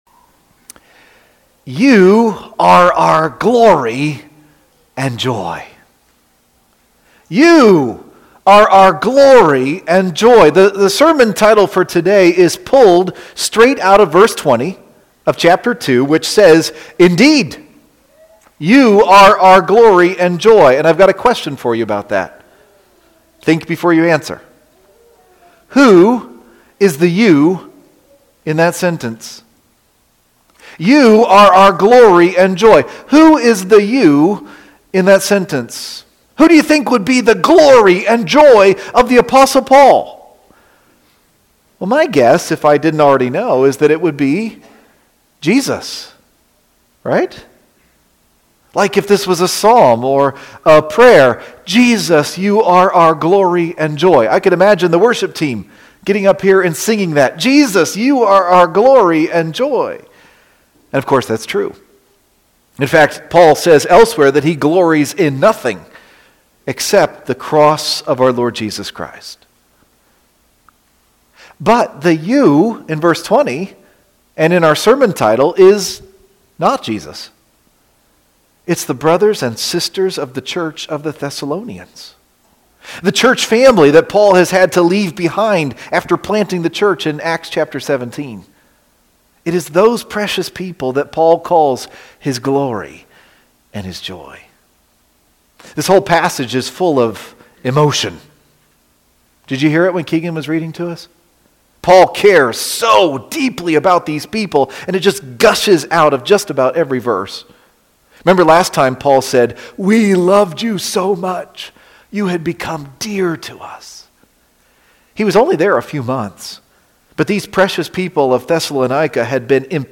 preaching on 1 Thessalonians 2:17-3:13